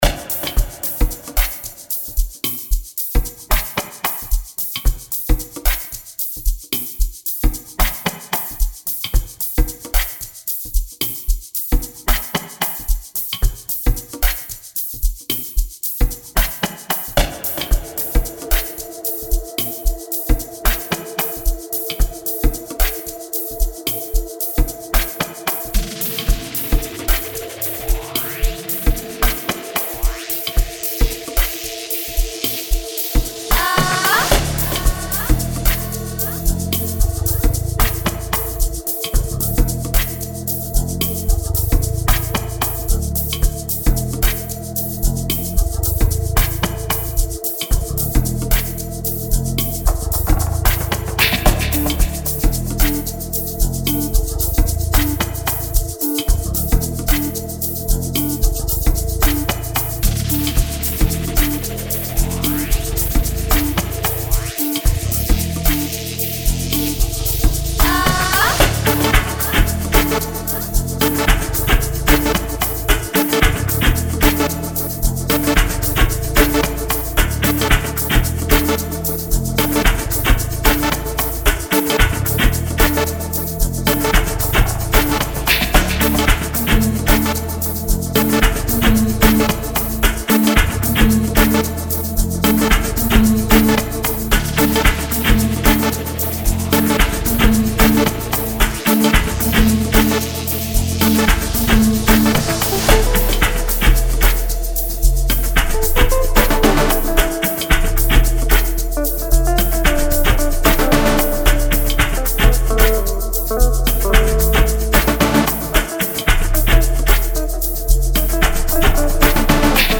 Amapiano music